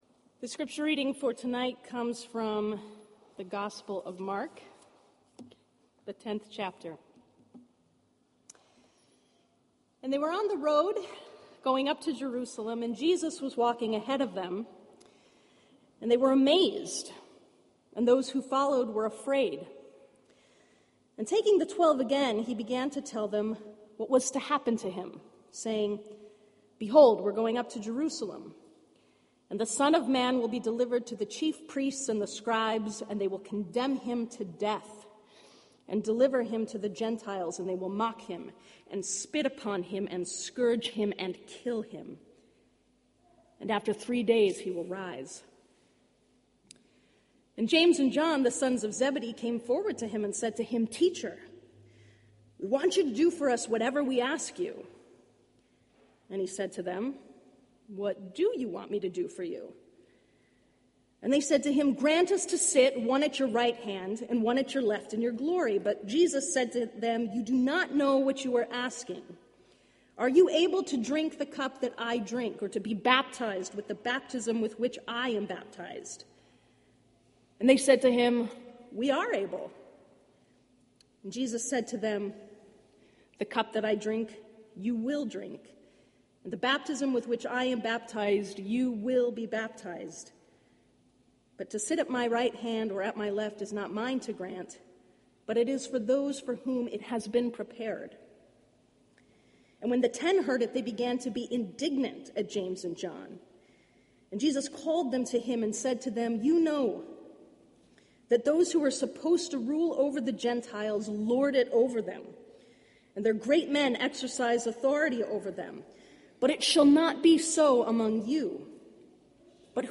Sermons .